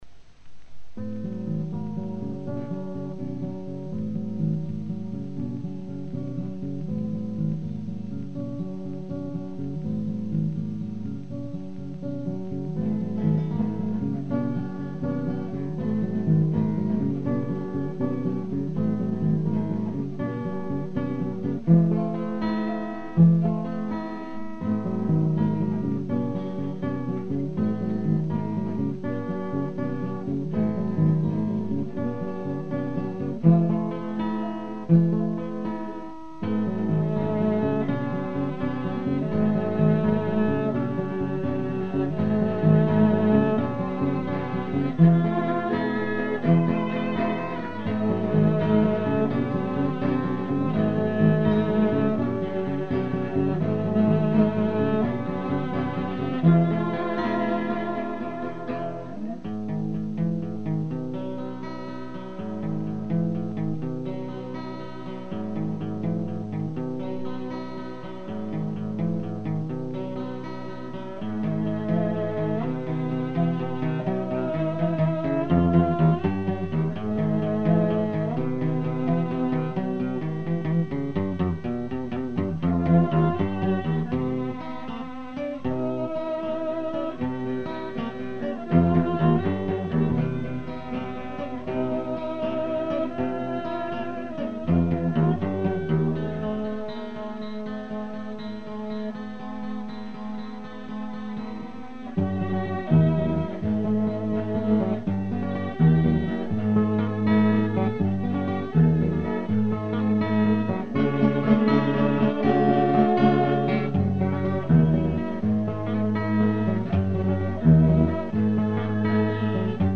Акустика
перкуссия
альт